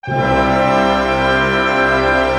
Index of /90_sSampleCDs/Roland LCDP08 Symphony Orchestra/ORC_ChordCluster/ORC_Pentatonic